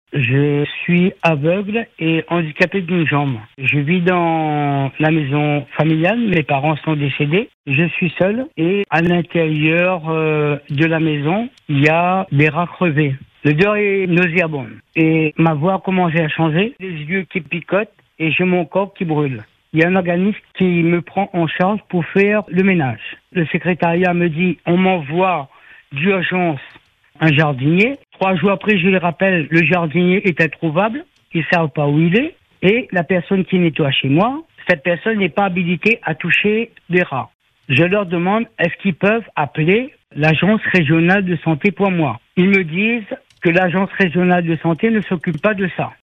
C’est un appel à l’aide bouleversant que nous avons reçu sur nos ondes.